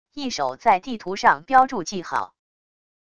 一手在地图上标注记号wav音频